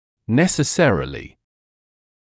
[ˌnesə’serelɪ][ˌнэсэ’сэрэли]обязательно, непременно; неизбежно